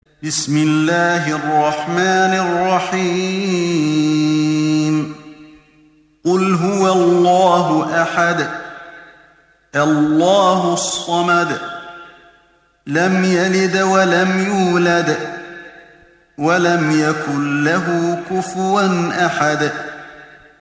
تحميل سورة الإخلاص mp3 بصوت علي الحذيفي برواية حفص عن عاصم, تحميل استماع القرآن الكريم على الجوال mp3 كاملا بروابط مباشرة وسريعة